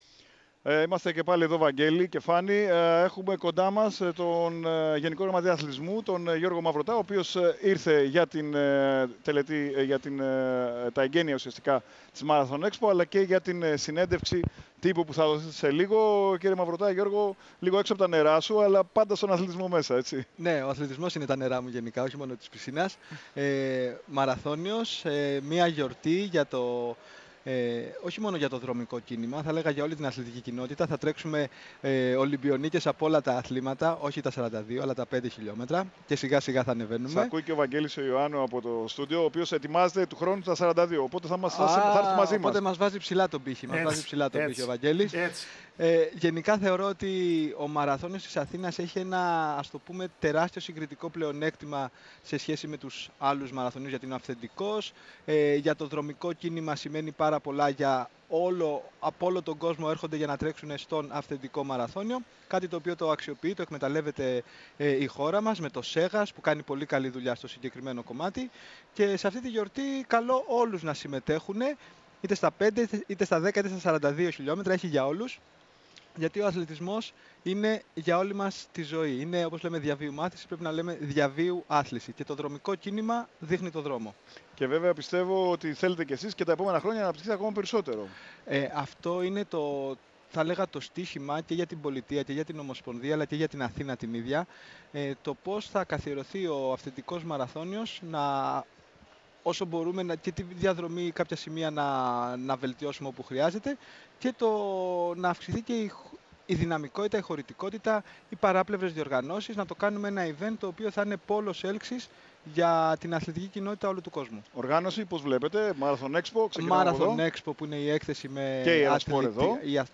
Ο Γενικός Γραμματέας Αθλητισμού, μίλησε στον αέρα της ΕΡΑ ΣΠΟΡ, από τη Marathon Expo, για στον Αυθεντικό Μαραθώνιο της Αθήνας,
Ο Γιώργος Μαυρωτάς, μίλησε στην ΕΡΑ ΣΠΟΡ, από τα εγκαίνια της Marathon Expo στο Γήπεδο Ταε-Κβο-Ντο, για τον Αυθεντικό Μαραθώνιο της Αθήνας αλλά και για το γεγονός πως άνθρωποι απ’ όλο τον κόσμο επισκέπτονται τη χώρα μας για να συμμετάσχουν σ’ αυτόν.//